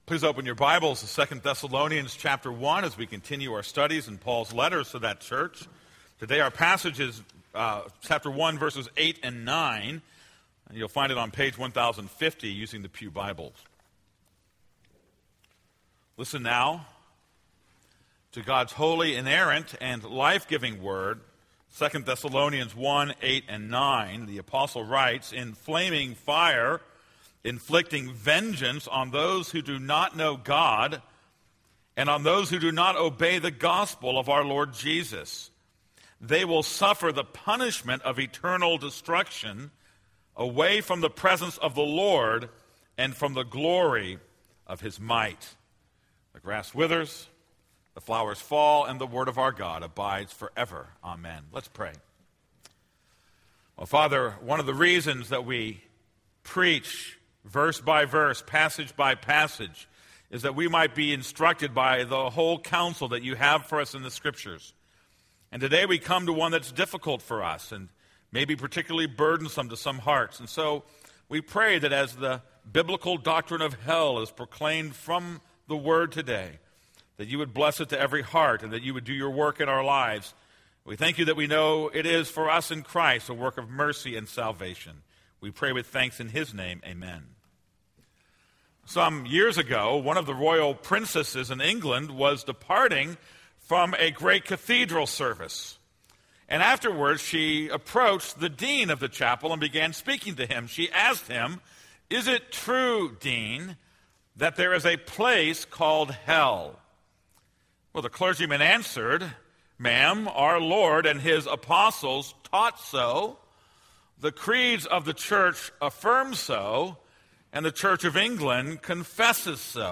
This is a sermon on 2 Thessalonians 1:8-9.